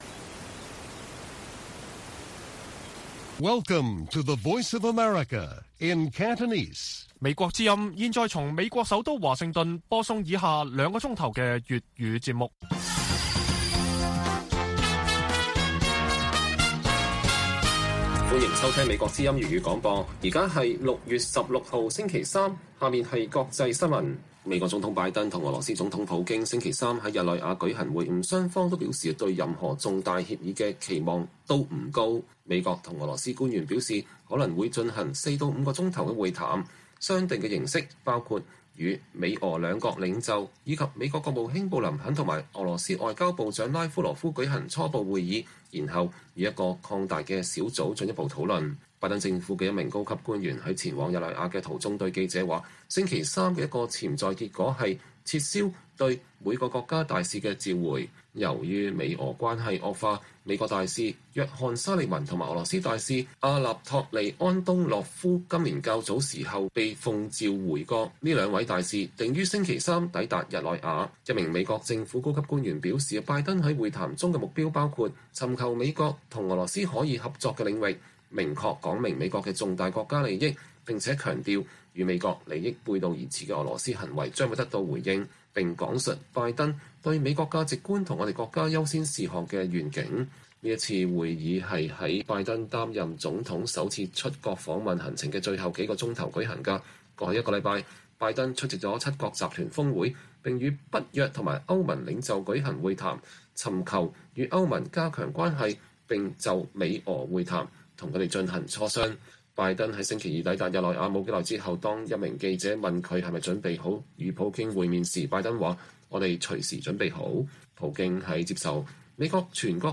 粵語新聞 晚上9-10點: 美俄領袖峰會在日內瓦召開